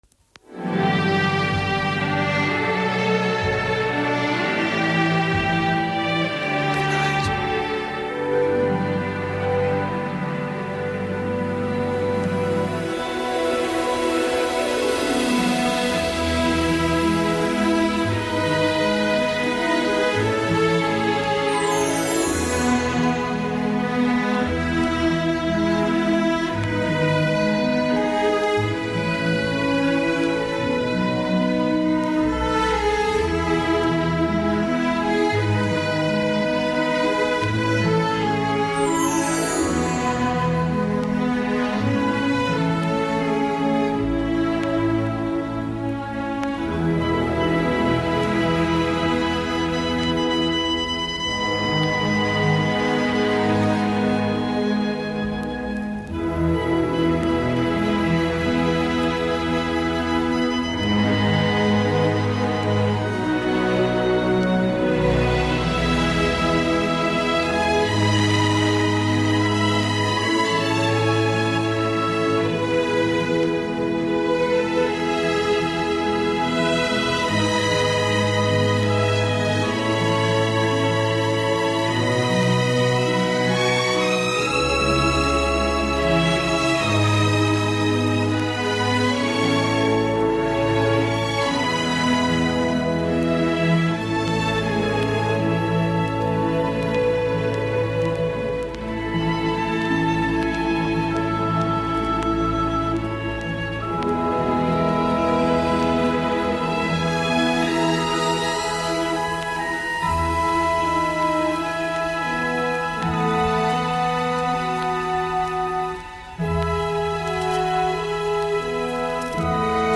Sintonia del programa, la "inspiració", hora, telèfon i primera trucada